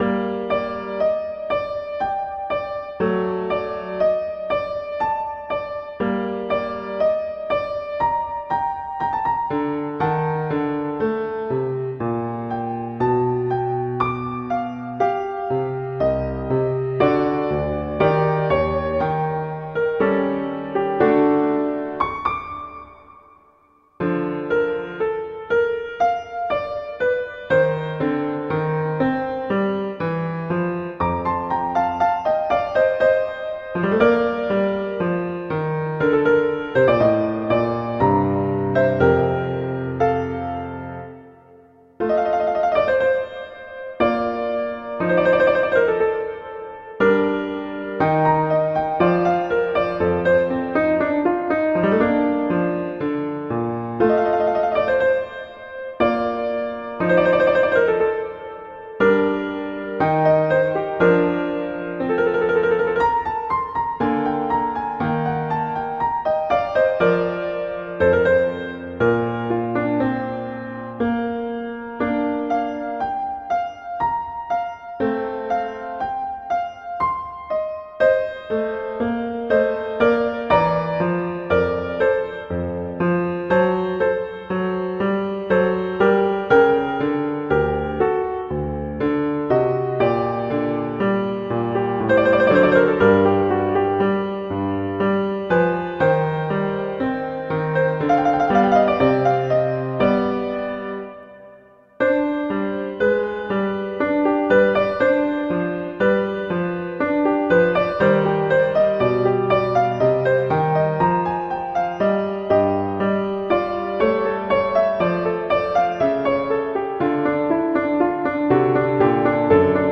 Instrumentation: piano solo
classical
Andante